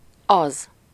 Ääntäminen
Ääntäminen France: IPA: /sə/ Tuntematon aksentti: IPA: /kɑz/ Lyhenteet ja supistumat c' Haettu sana löytyi näillä lähdekielillä: ranska Käännös Ääninäyte 1. ez 2. az Suku: m .